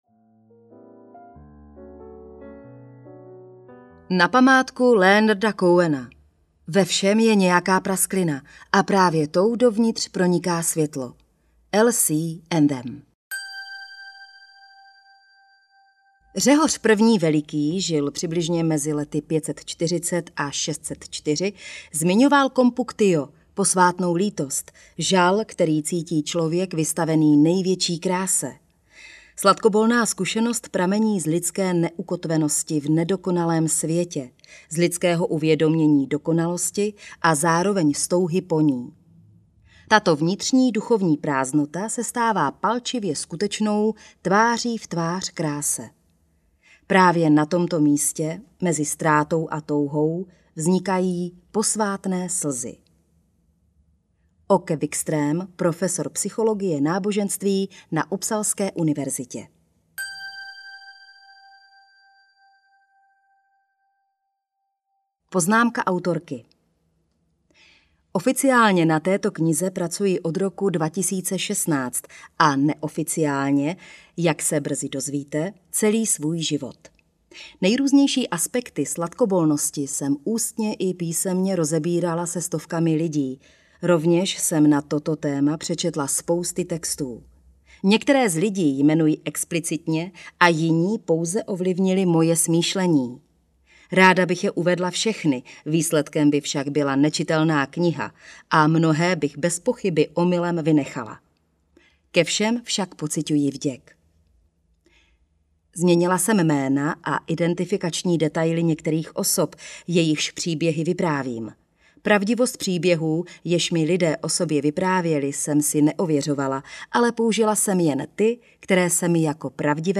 Sladkobol audiokniha
Ukázka z knihy
sladkobol-audiokniha